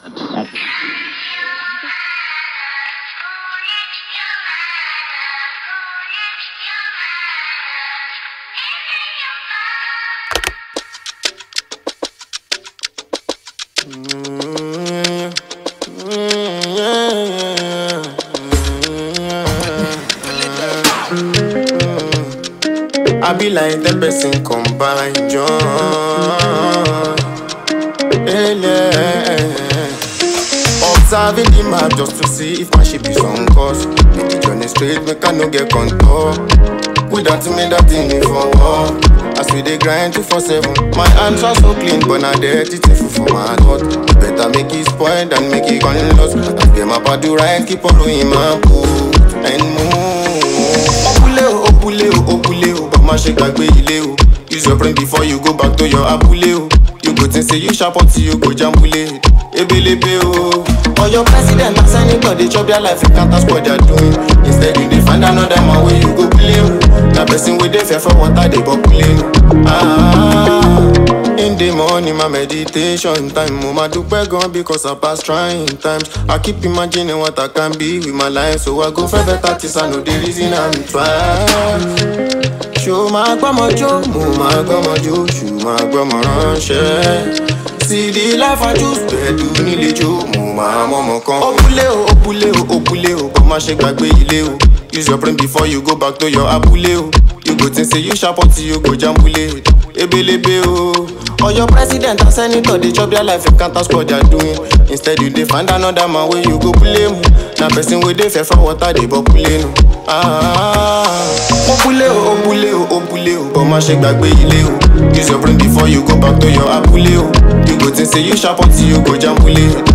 street-pop